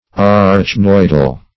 Search Result for " arachnoidal" : The Collaborative International Dictionary of English v.0.48: Arachnoidal \Ar`ach*noid"al\, a. (Anat.) Pertaining to the arachnoid membrane; arachnoid.